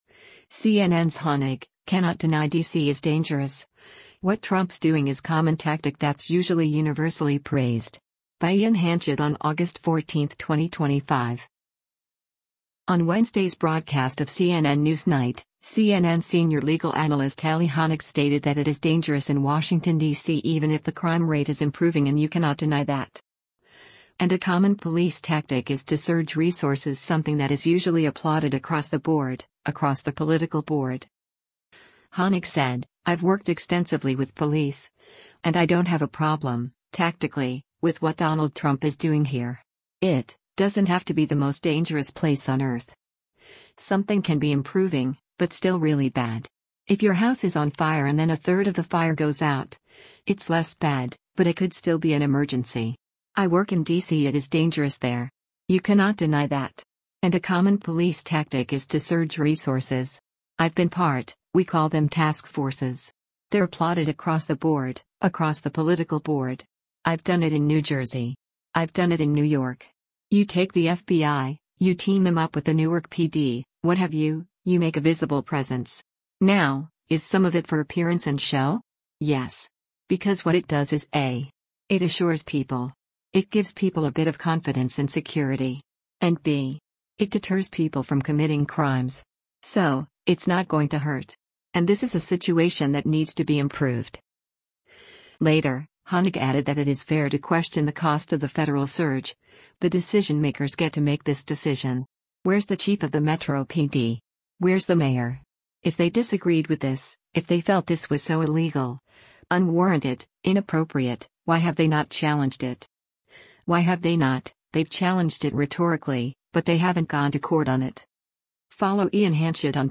On Wednesday’s broadcast of “CNN NewsNight,” CNN Senior Legal Analyst Elie Honig stated that “It is dangerous” in Washington, D.C. even if the crime rate is improving and “You cannot deny that. And a common police tactic is to surge resources” something that is usually “applauded across the board, across the political board.”